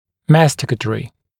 [‘mæstɪkət(ə)rɪ][‘мэстикэт(э)ри]жевательный